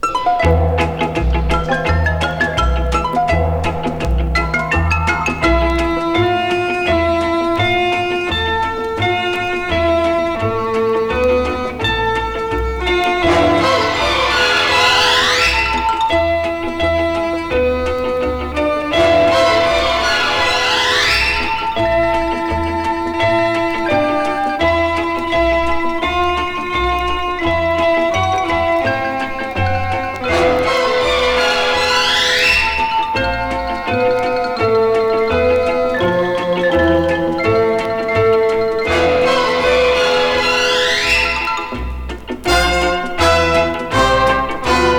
World, Easy Listening, Pops　USA　12inchレコード　33rpm　Mono
盤擦れキズ　A1と2音に出るキズ有